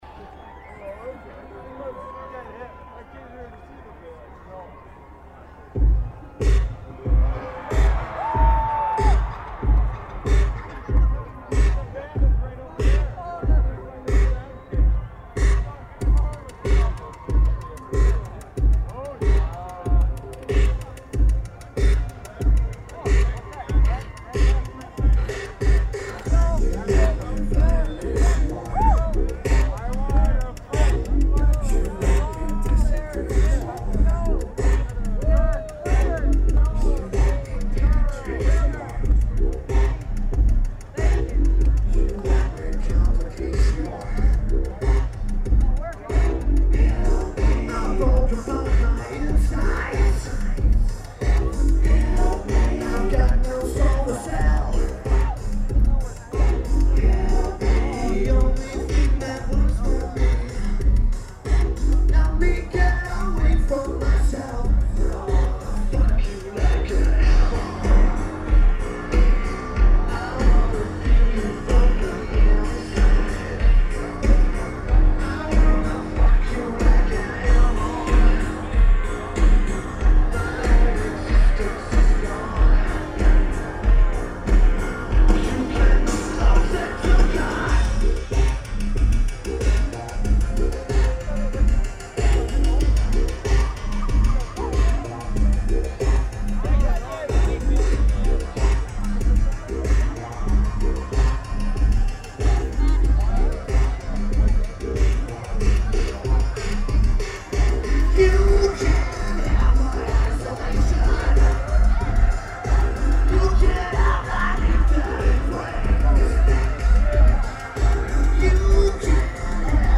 Barrie, Ontario Canada
Lineage: Audio - AUD (Sennheiser MKE2002s + Sony TCD-D8)
Good recording.